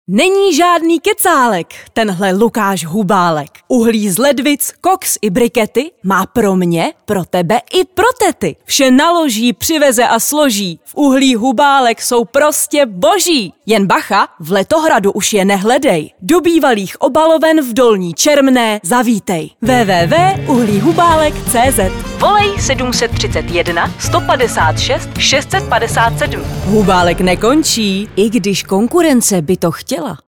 Umím: Voiceover